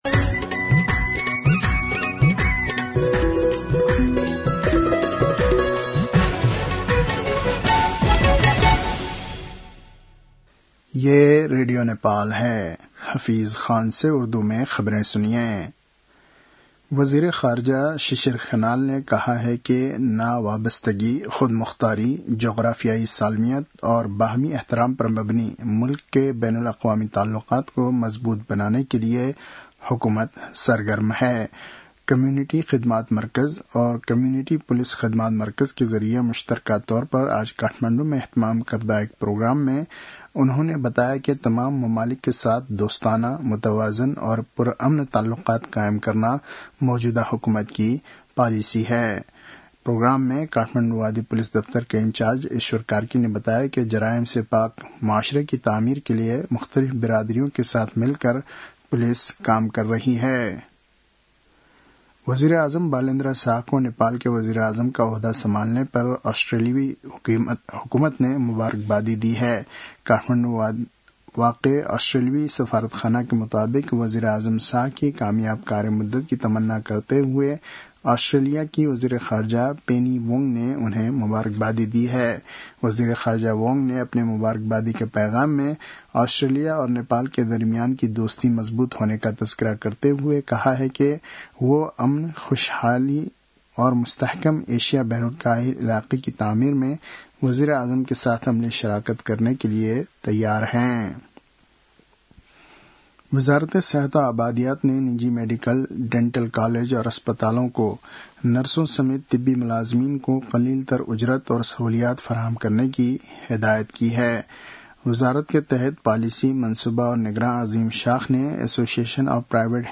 उर्दु भाषामा समाचार : २१ चैत , २०८२